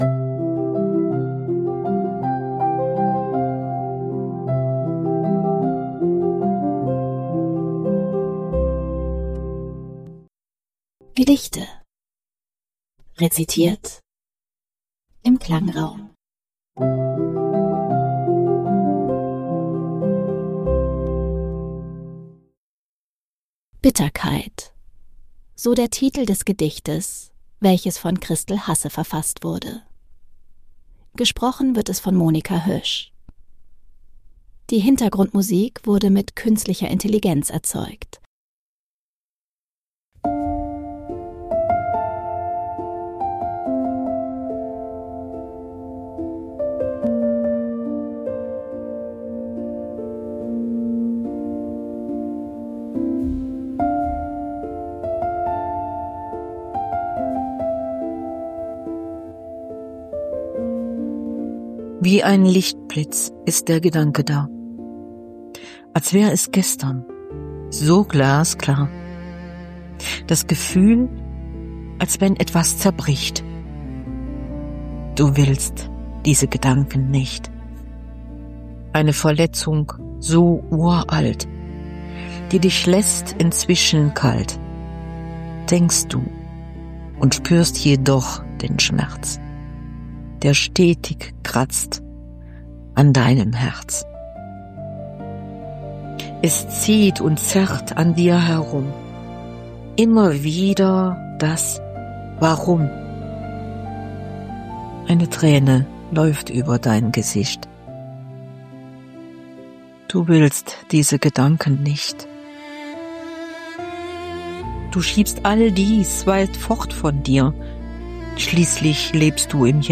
Die Begleitmusik wurde mit Künstlicher
Intelligenz erzeugt. 2026 GoHi (Podcast) - Kontakt: